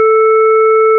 Sur les différentes représentations des courbes suivantes, les fréquences et les amplitudes sont identiques.
une harmonique s'ajoute sur la courbe parfaite